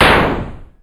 Shoot27.wav